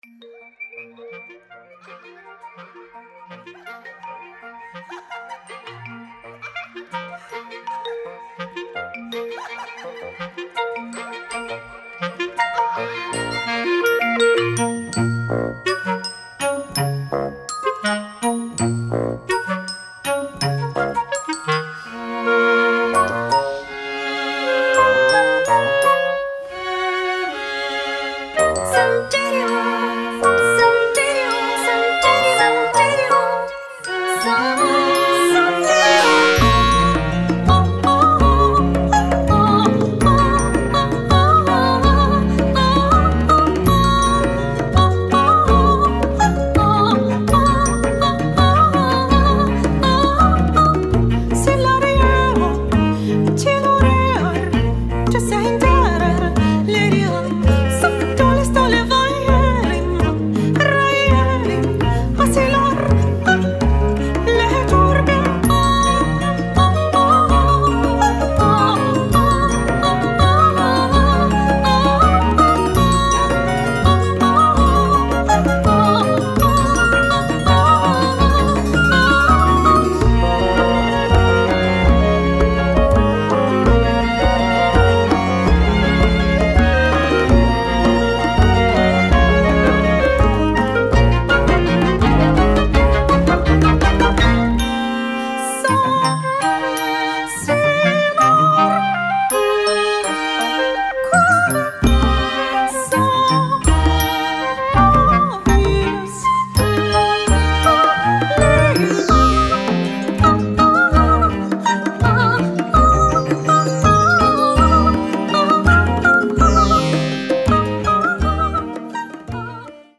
"неоклассика" - от академизма до прогрессивного фолка.